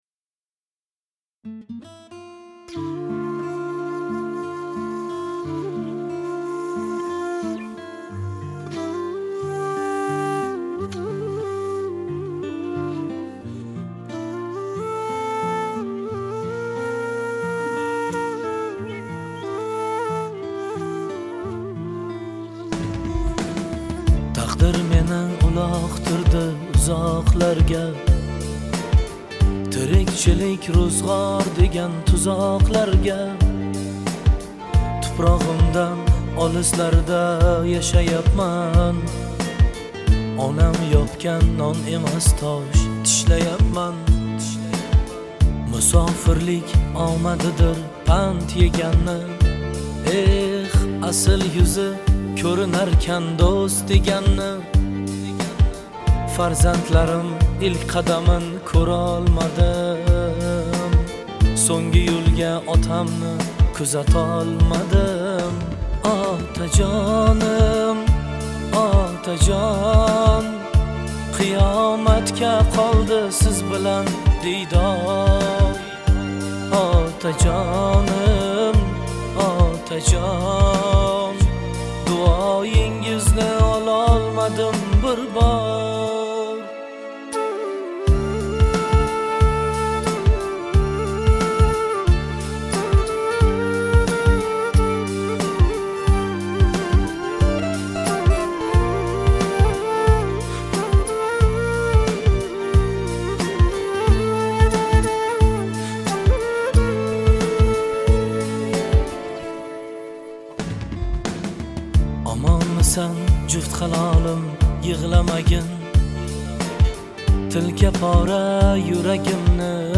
Трек размещён в разделе Узбекская музыка / Поп / 2022.